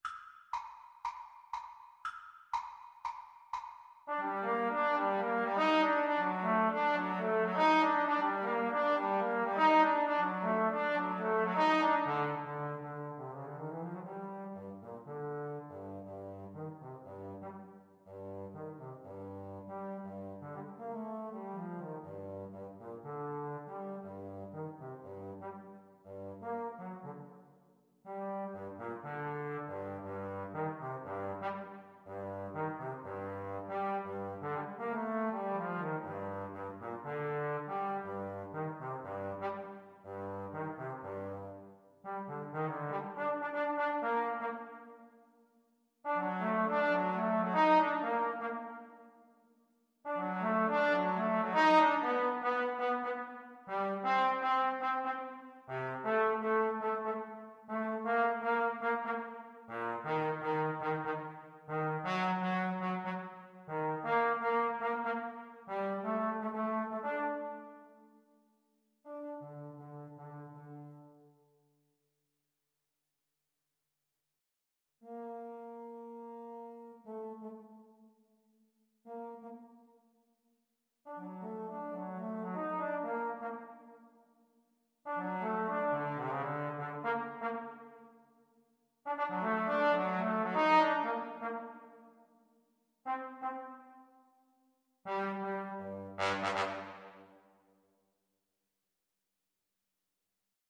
A firey salsa-inspired piece.
Energico =120
4/4 (View more 4/4 Music)
Jazz (View more Jazz Trombone Duet Music)